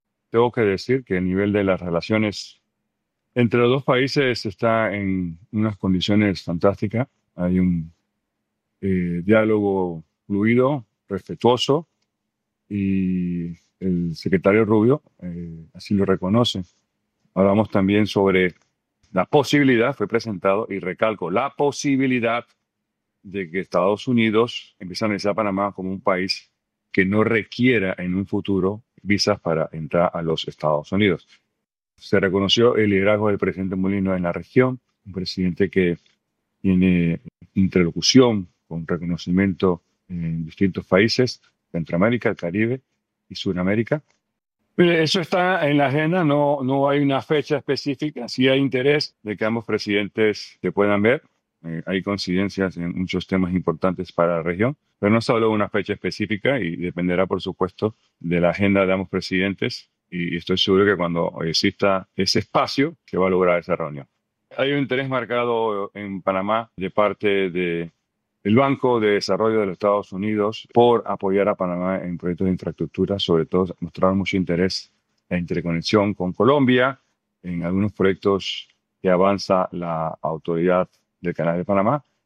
La posibilidad de que en un futuro los ciudadanos panameños no necesiten visado para ingresar a Estados Unidos, el interés del presidente Donald Trump de reunirse en un futuro con el presidente José Raúl Mulino y el resultado del encuentro este martes con el secretario de Estado, Marco Rubio, fueron algunos de los temas tratados por el ministro de Relaciones Exteriores, Javier Martínez-Acha Vásquez en una entrevista este miércoles en el programa Cuarto Poder de Medcom.